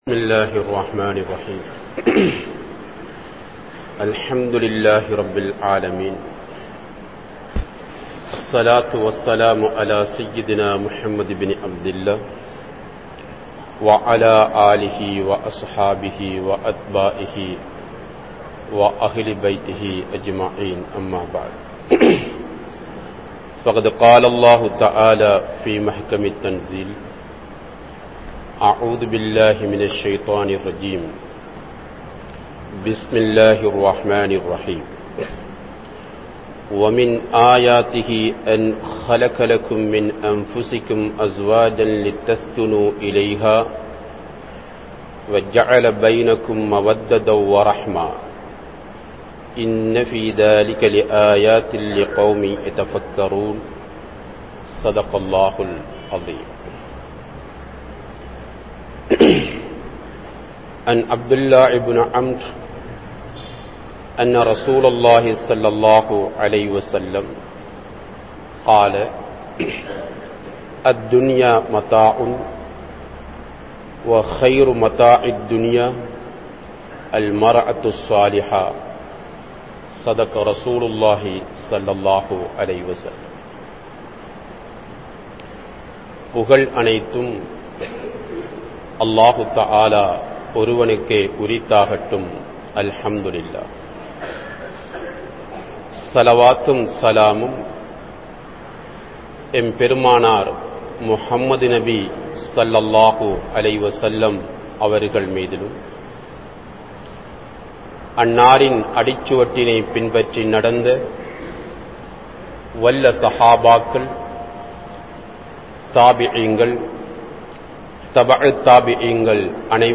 Mana Mahan & Mana Mahal Therivu (மணமகன் & மணமகள் தெரிவு) | Audio Bayans | All Ceylon Muslim Youth Community | Addalaichenai
Jamiul Akbar Jumua Masjidh